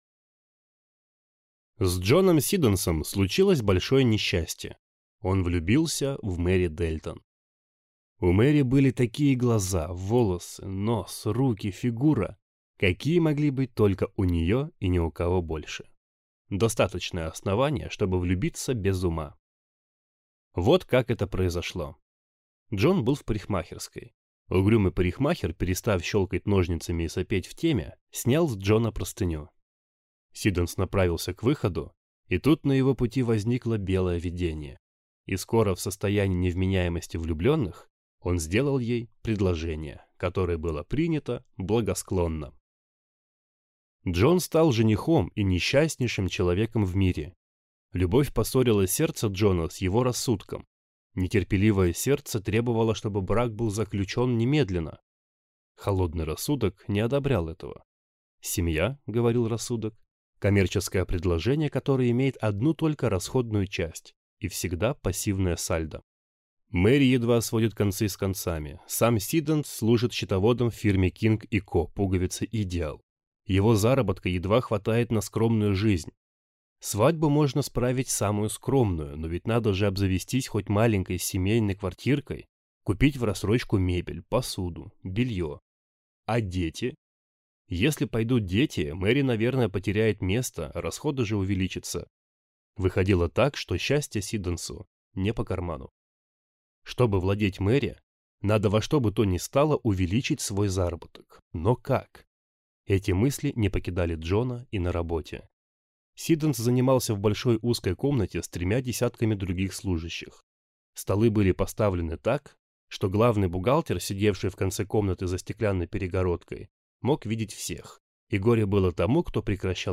Аудиокнига Анатомический жених | Библиотека аудиокниг